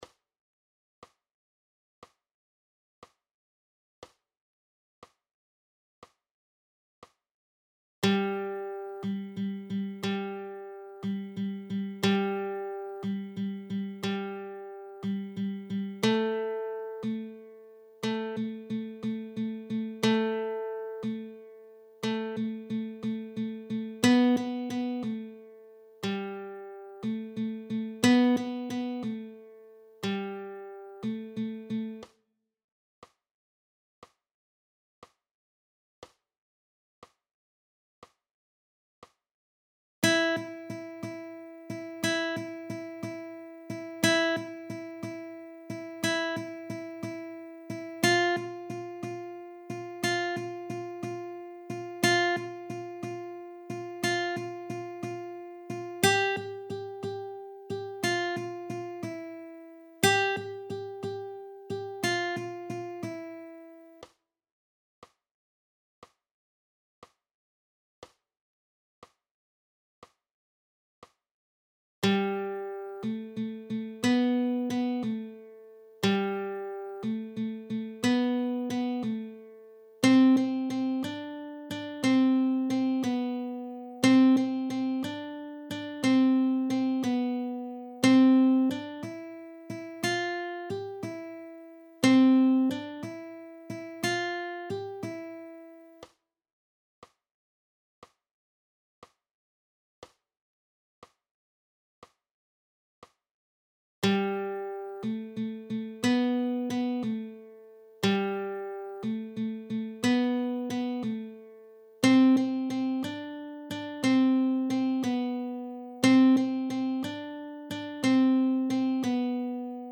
I. Achtel-Triolen … Shuffle; Melodiespiel mit Stammtönen: PDF
+ Audio 60 bpm:
I.-Achtel-Triolen-.-Shuffle.neump3_.mp3